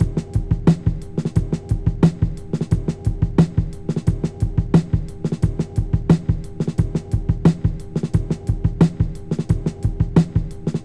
Drum Loop 2.wav